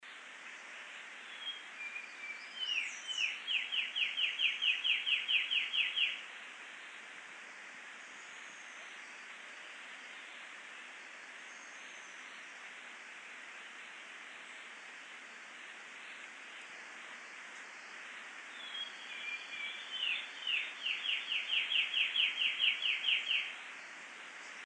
3. Eastern Towhee (Pipilo erythrophthalmus)
Sound: “Drink your teeeee!” is how birders often describe their cheerful tune.